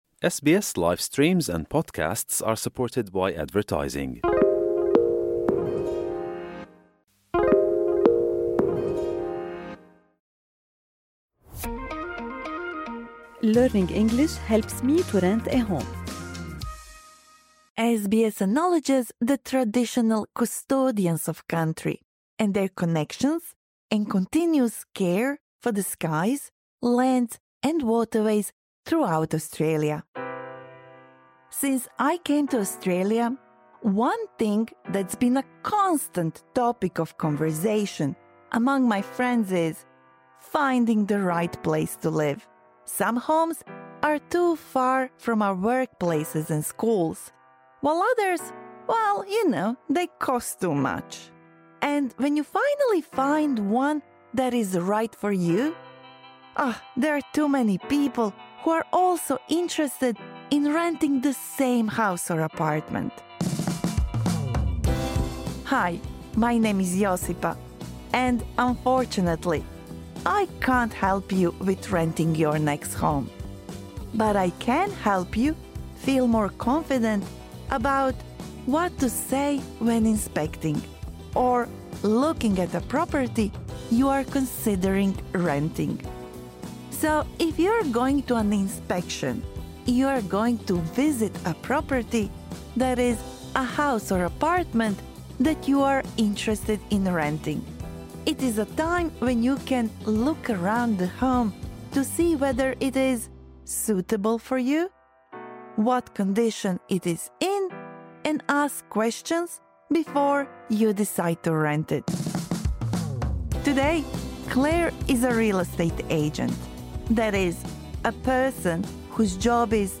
This lesson is suitable for intermediate-level learners.